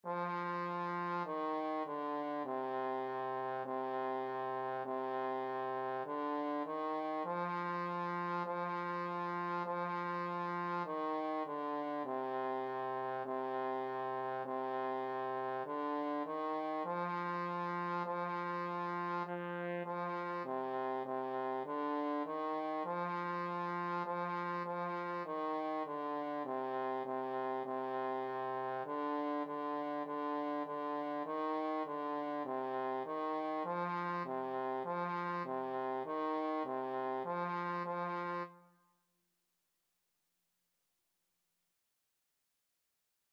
4/4 (View more 4/4 Music)
C4-F4
Beginners Level: Recommended for Beginners
Trombone  (View more Beginners Trombone Music)
Classical (View more Classical Trombone Music)